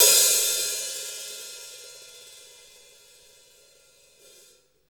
HAT ROCK H0A.wav